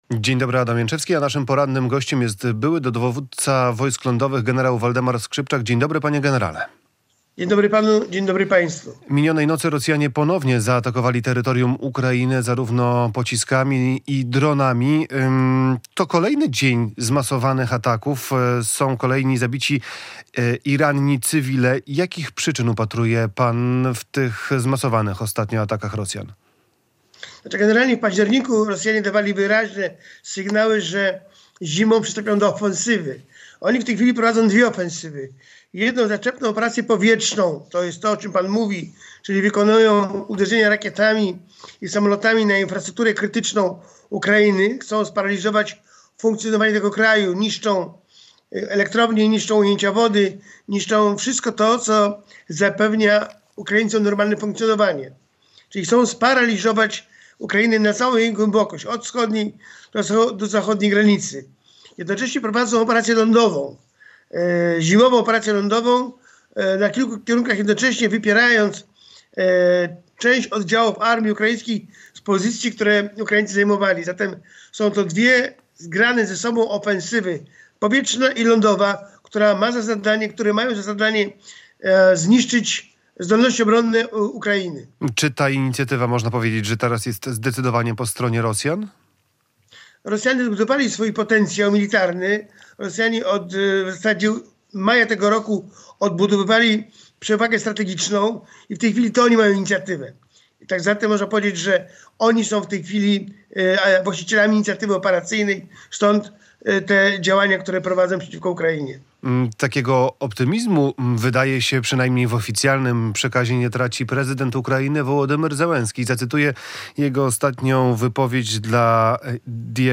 były dowódca Wojsk Lądowych RP